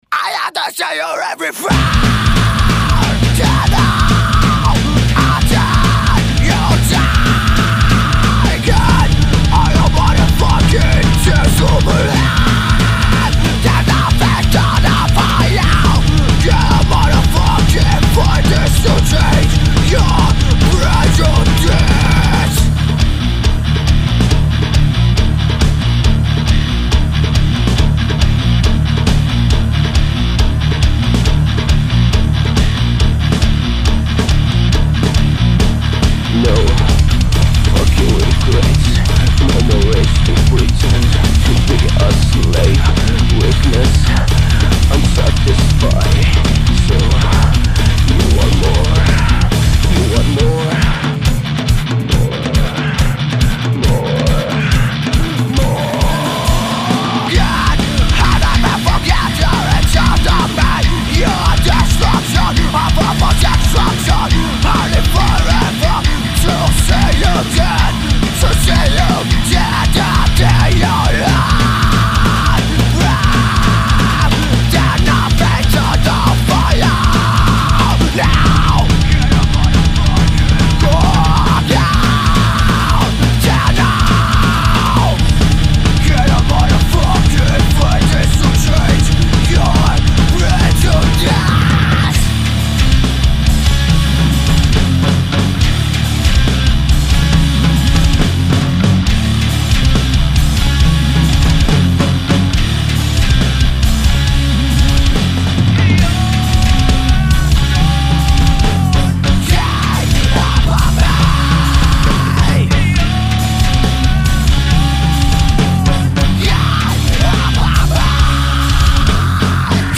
Thrash/hardcore